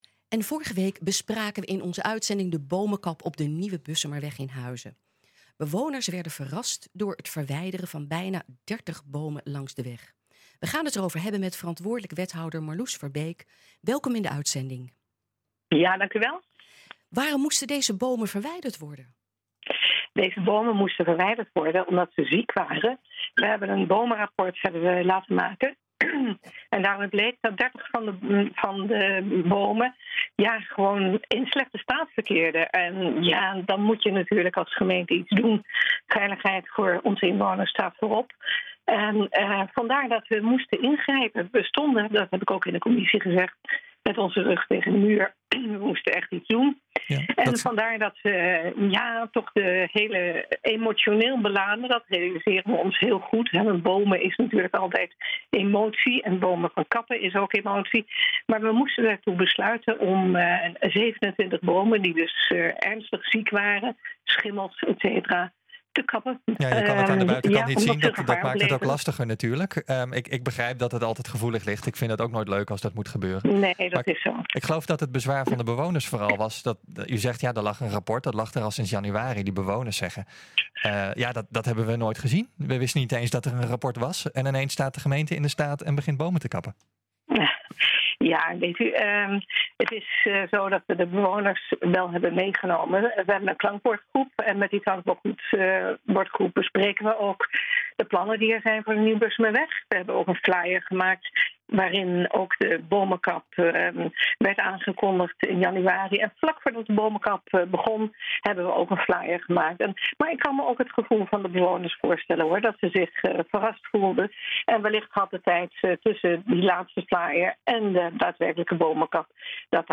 Verantwoordelijk wethouder Marlous Verbeek reageert vandaag in het radioprogramma NH Gooi.